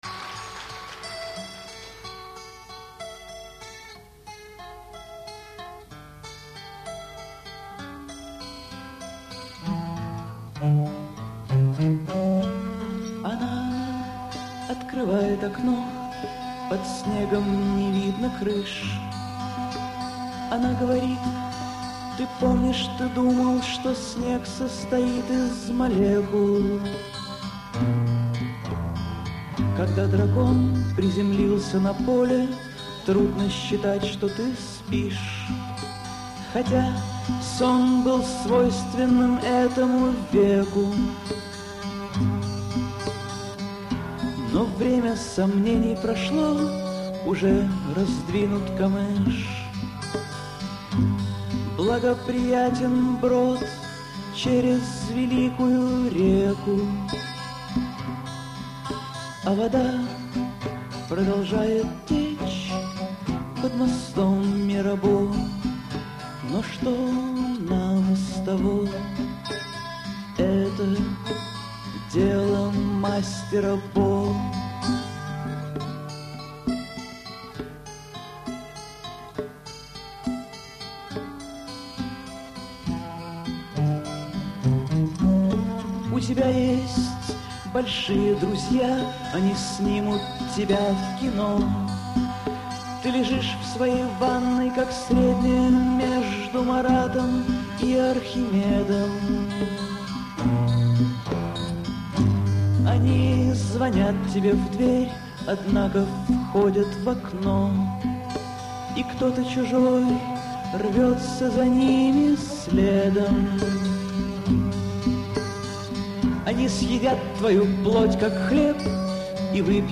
Акустический концерт